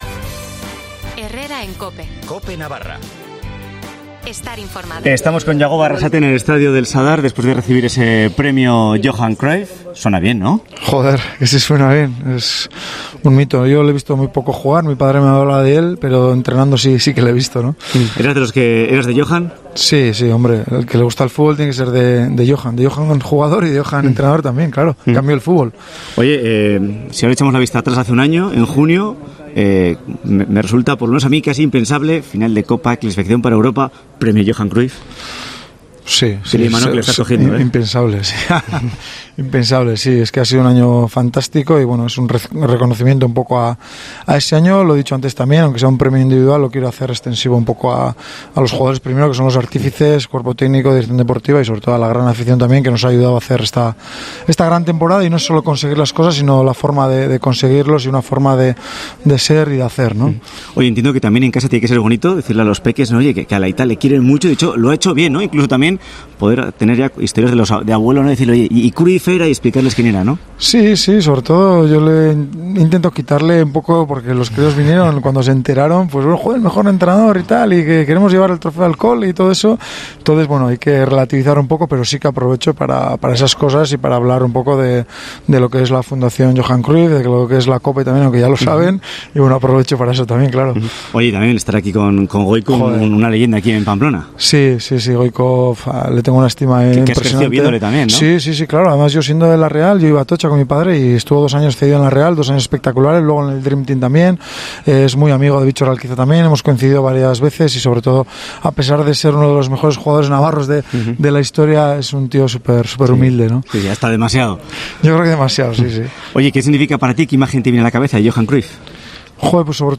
Jagoba Arrasate habla tras recibir el premio Johan Cruyff como mejor entrenador
ARRASATE RECIBE EL PREMIO EN EL SADAR